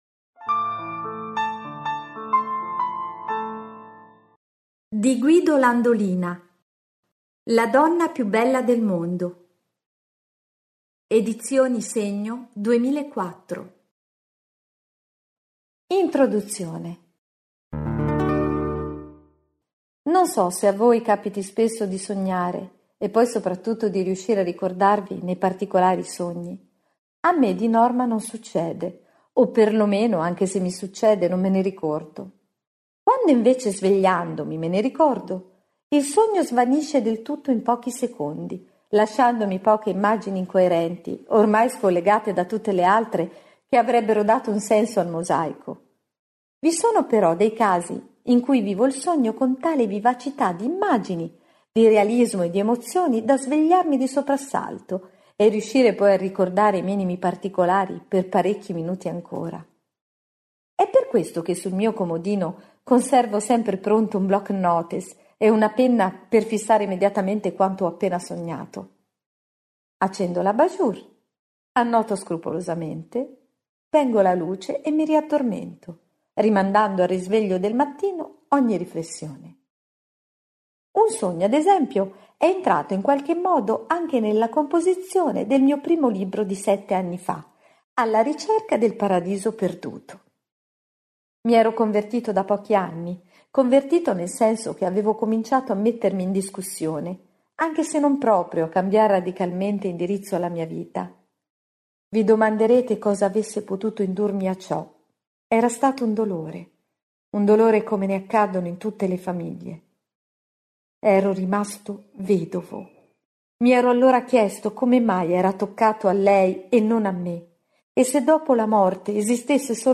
Voce narrante